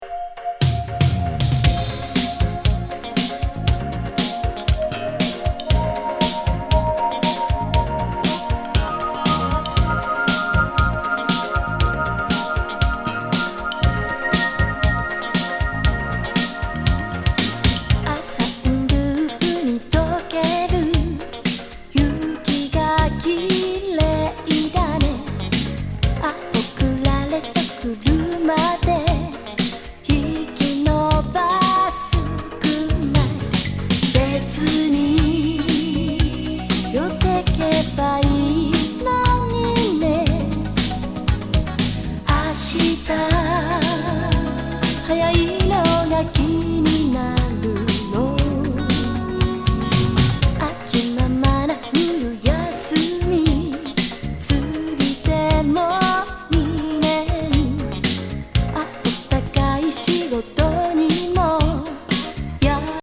雪景色ディスコ